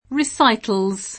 riS#itëN@]) — anche nella forma fr. récital [reSit#l]; pl. récitals [id.]